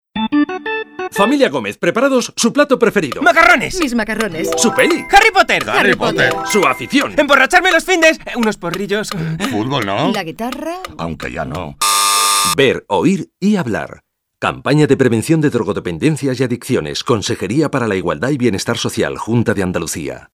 1. Emisión de cuñas de radio: